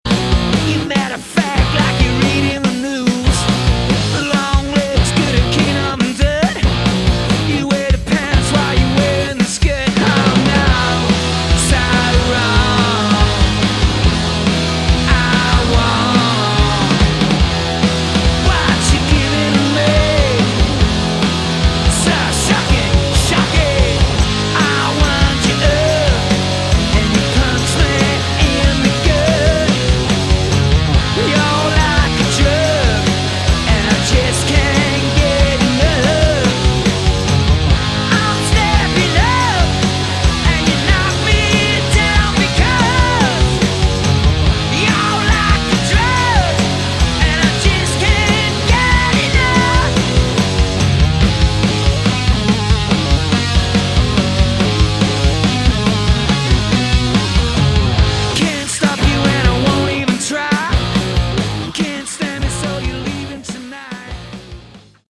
Category: Sleazy Hard Rock
vocals
electric & acoustic guitars, Mellotron
bass
drums (studio), strings